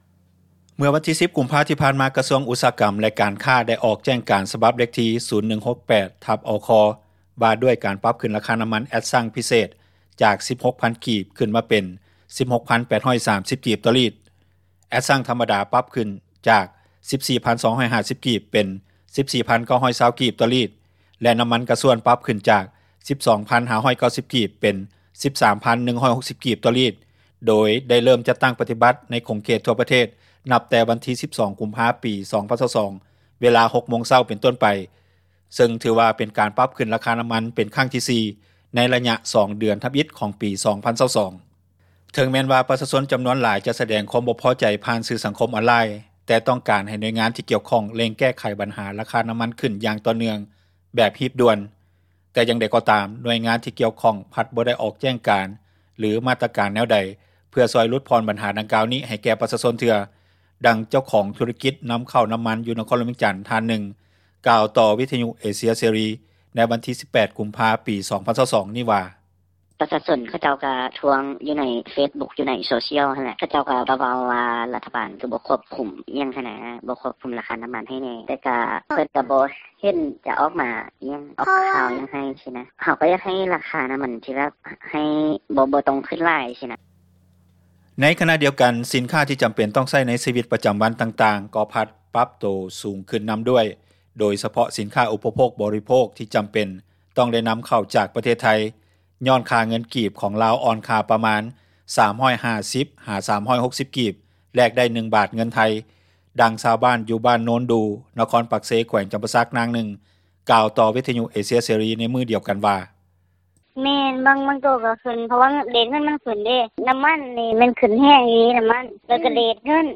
ດັ່ງພະນັກງານຣັຖກອນ ທີ່ມີລາຍໄດ້ເດືອນລະປະມານ 1.3 ລ້ານກີບ ທ່ານນຶ່ງກ່າວວ່າ:
ດັ່ງຊາວບ້ານ ຢູ່ເມືອງໄຊທານີ ນະຄອນຫລວງວຽງຈັນ ອີກນາງນຶ່ງກ່າວວ່າ: